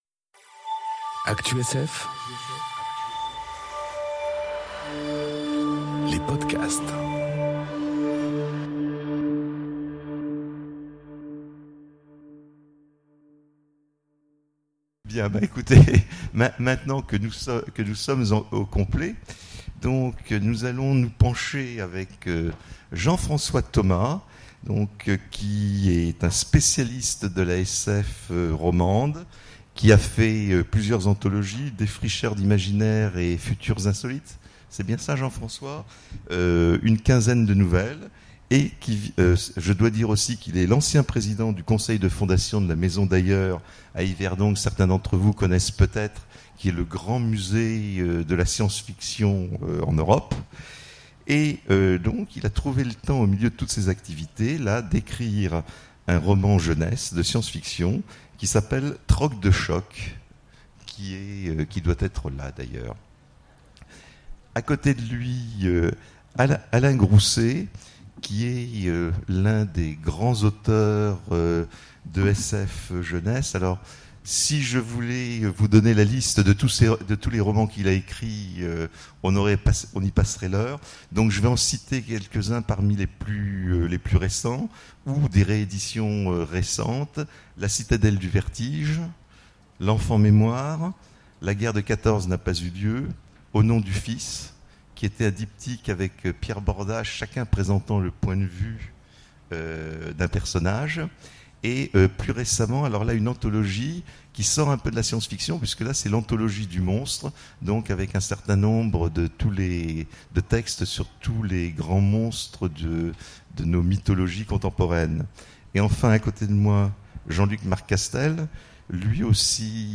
Conférence Récits pour bons jeunes... À conseiller aux parents aimants ! enregistrée aux Imaginales 2018